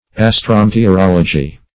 Meaning of astrometeorology. astrometeorology synonyms, pronunciation, spelling and more from Free Dictionary.
astrometeorology.mp3